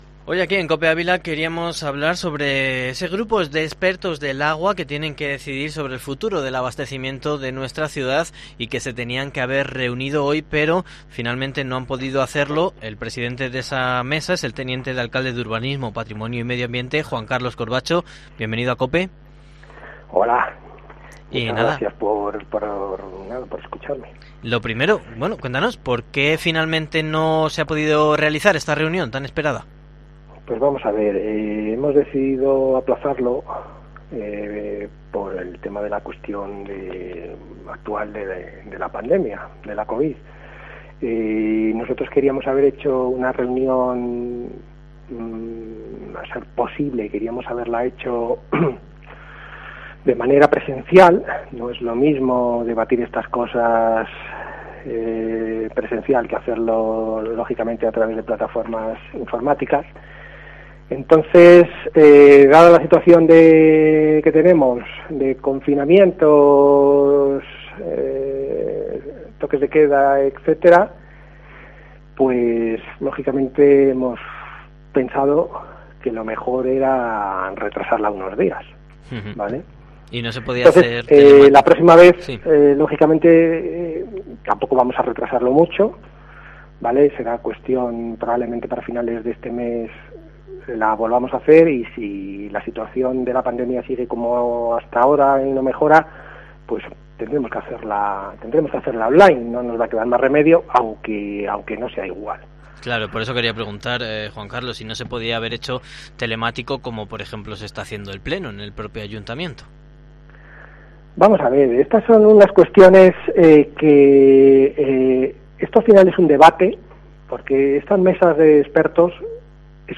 Entrevista en COPE con el teniente de alcalde Juan Carlos Corbacho